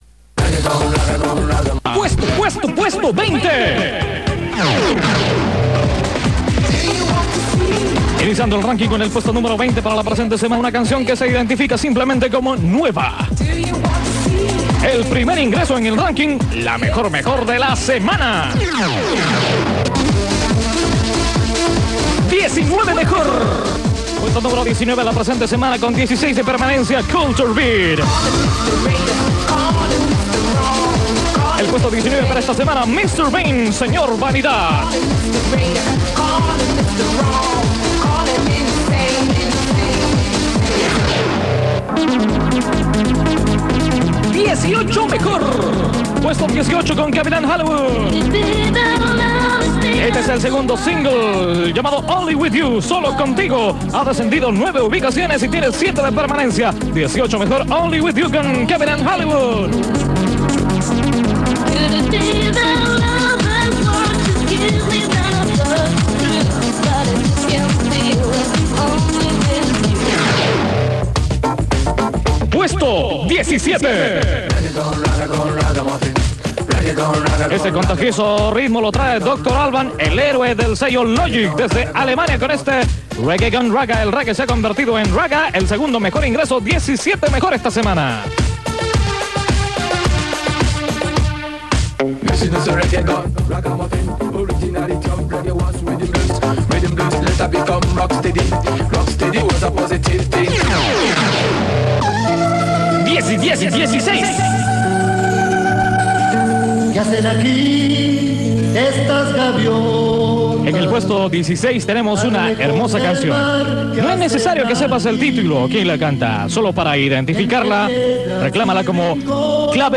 /uploads/monthly_2020_10/RANKING_1993_-_Radio_Studio_92_Lima_Perú.ogg.841f42148e48f86a96f5f760ac976d28.ogg">RANKING_1993_-_Radio_Studio_92_Lima_Perú.ogg